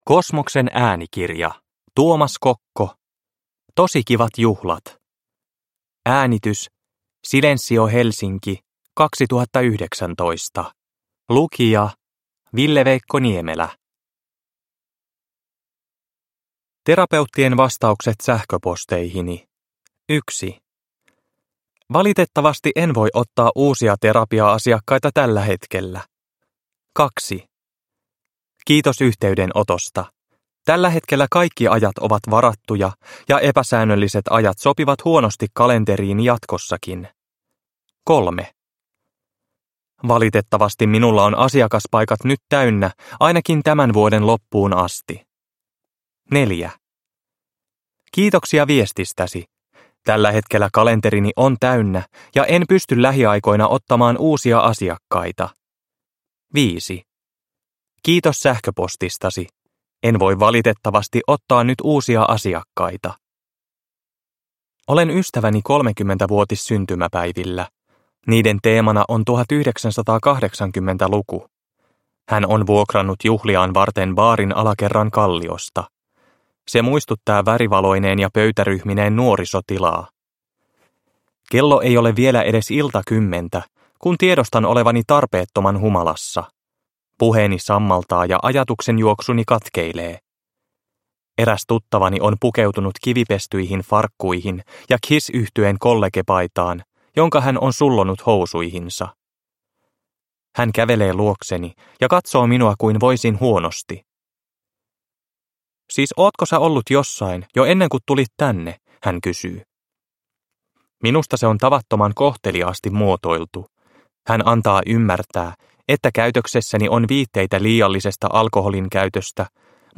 Tosi kivat juhlat – Ljudbok – Laddas ner